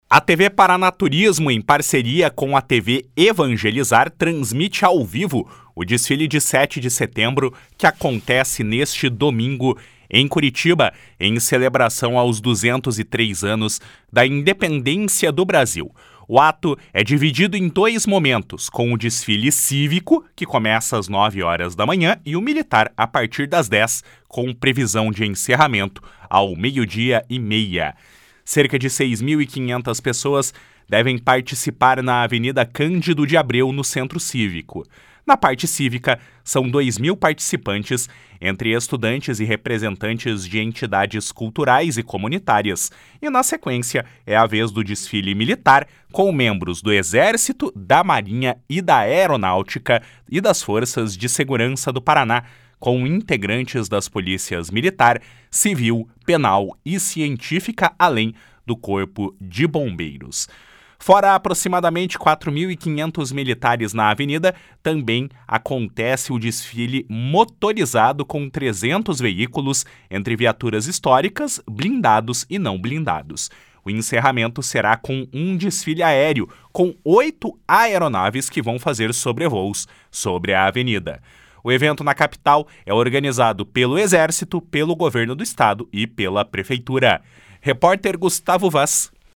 TV PARANA TUISMO VAI TRANSMITIR AO VIVO O DESFILE.mp3